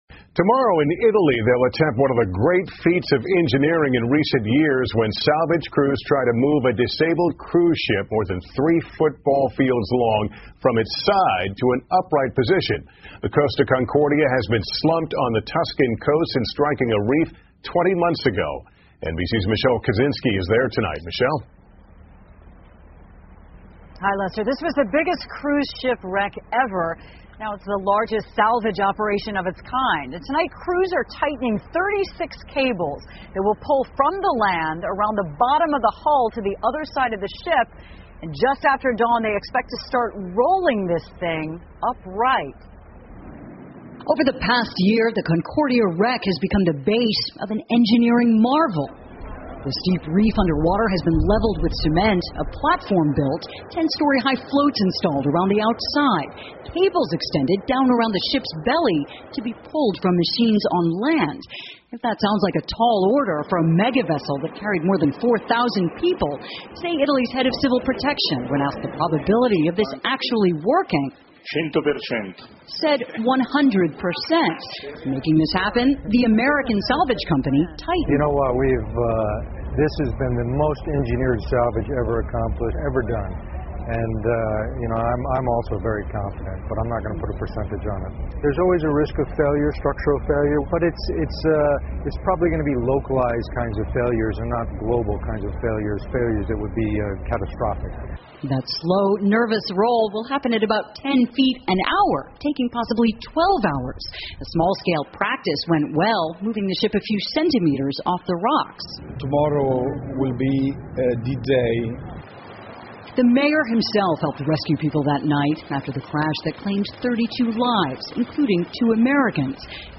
NBC晚间新闻 如何抬起一艘邮轮 听力文件下载—在线英语听力室